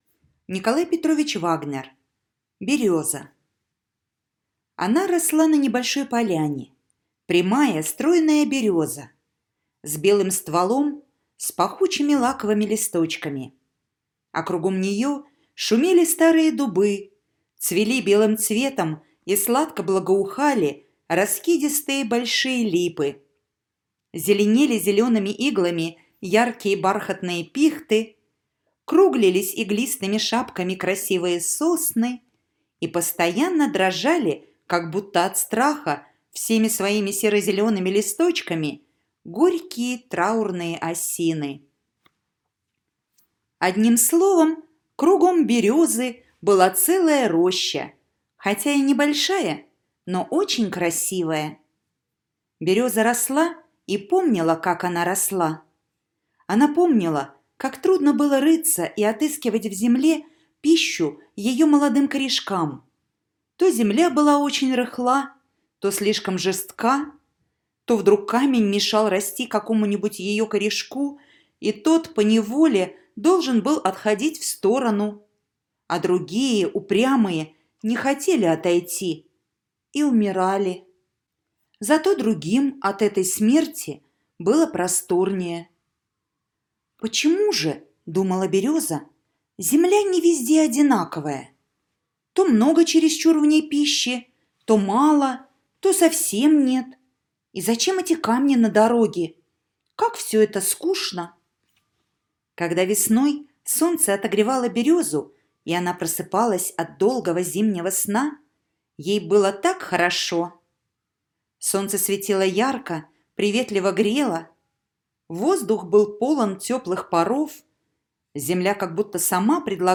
Береза - аудиосказка Вагнера - слушать онлайн